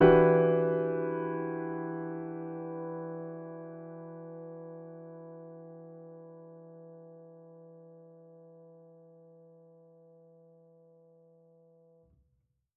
Index of /musicradar/jazz-keys-samples/Chord Hits/Acoustic Piano 1
JK_AcPiano1_Chord-Em9.wav